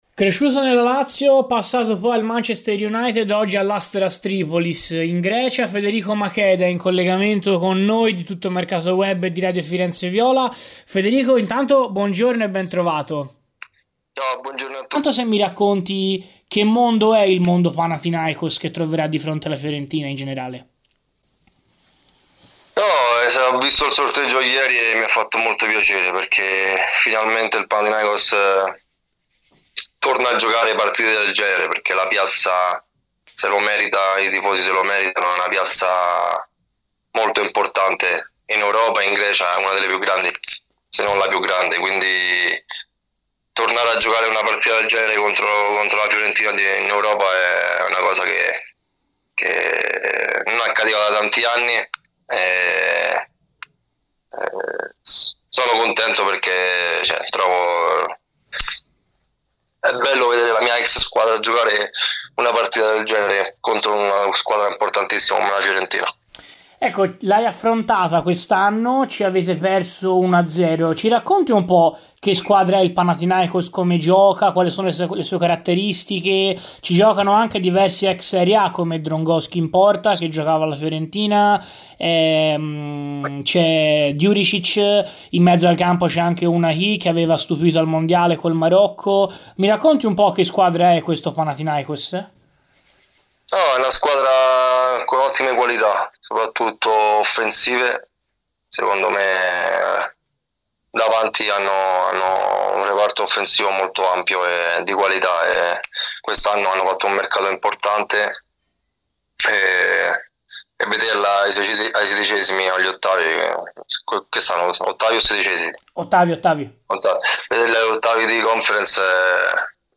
Che a Radio FirenzeViola analizza così l'imminente doppio confronto: “Ho visto il sorteggio ieri e mi ha fatto molto piacere, finalmente il Panathinaikos torna a giocare partite del genere.